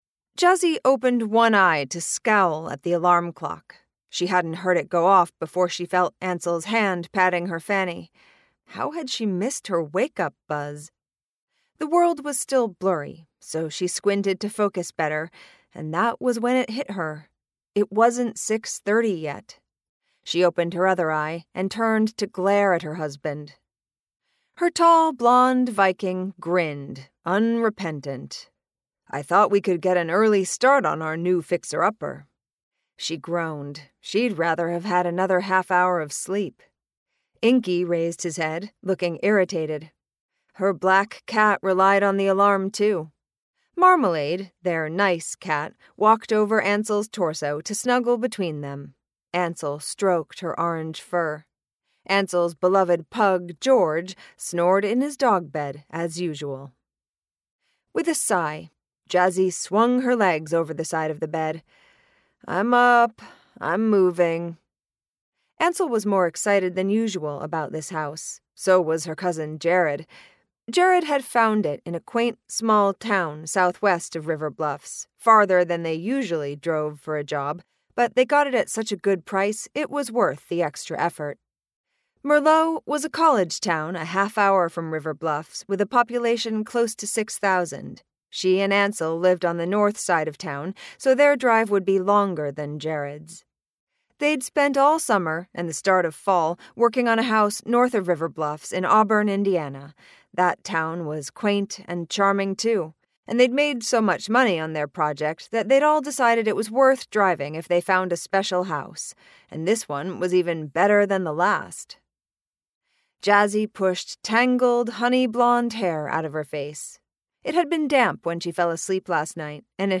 The Body from the Past - A Jazzi Zanders Mystery, Book Five - Vibrance Press Audiobooks - Vibrance Press Audiobooks